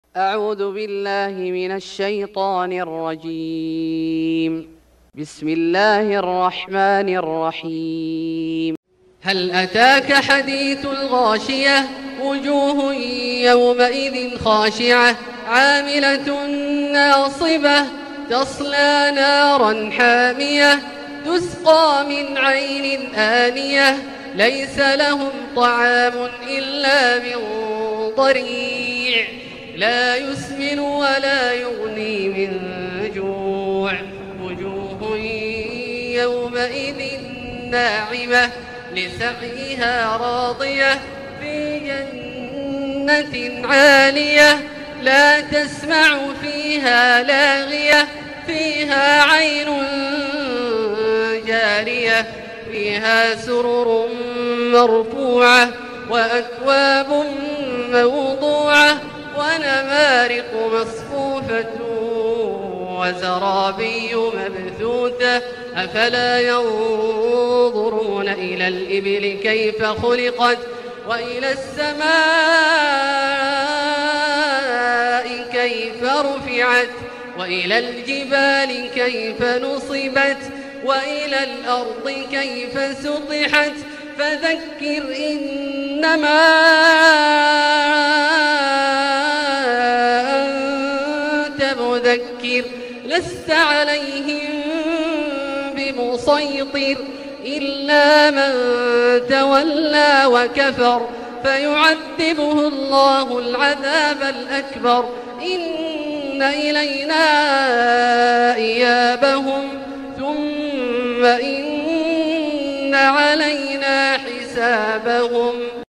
سورة الغاشية Surat Al-Ghashiyah > مصحف الشيخ عبدالله الجهني من الحرم المكي > المصحف - تلاوات الحرمين